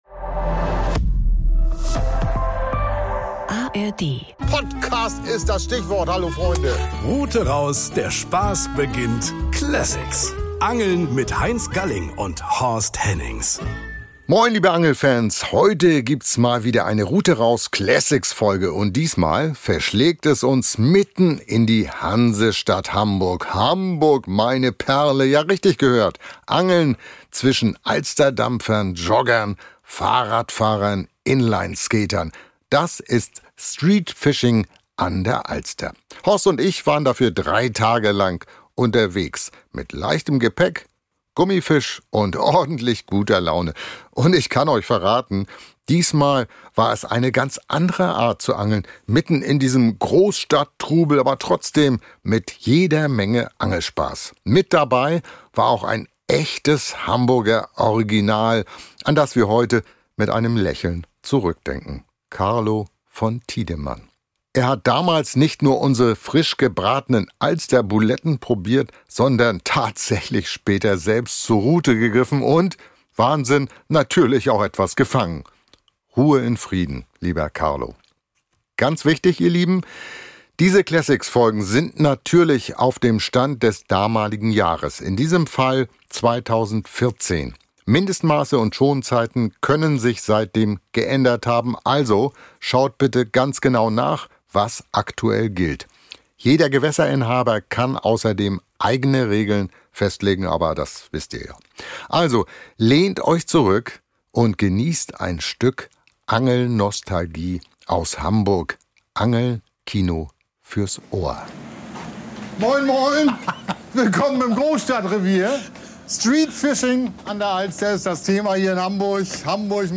Mit dabei: Gummifisch, Spinner, Maden – und jede Menge neugierige Passanten. Hinweis: Diese Classics-Folge stammt aus dem Jahr 2014.